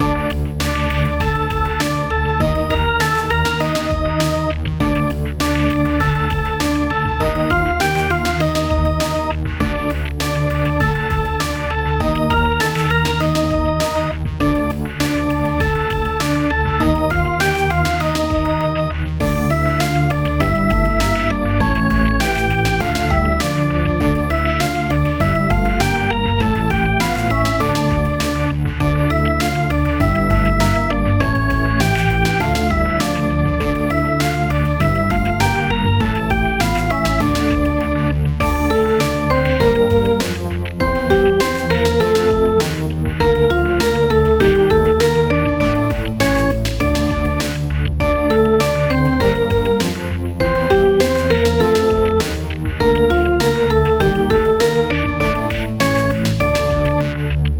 暗い楽曲
【イメージ】暗黒、悪 など